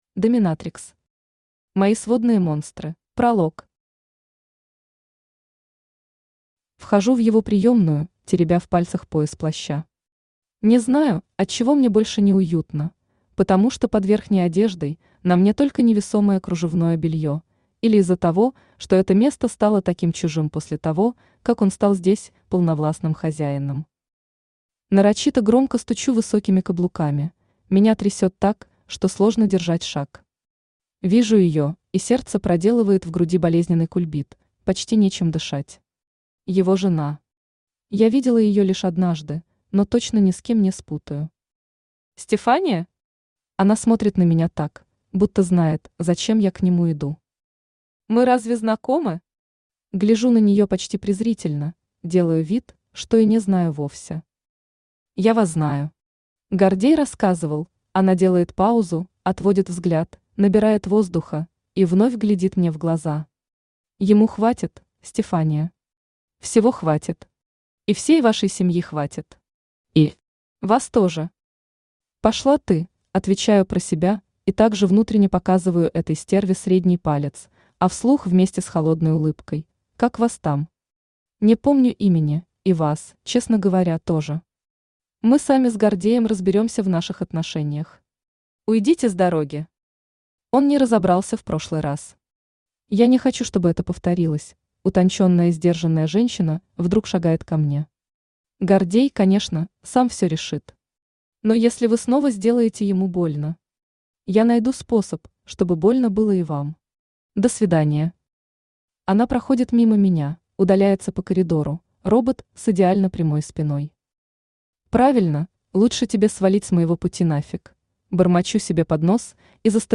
Аудиокнига Мои сводные монстры | Библиотека аудиокниг
Aудиокнига Мои сводные монстры Автор ДОМИНАТРИКС Читает аудиокнигу Авточтец ЛитРес.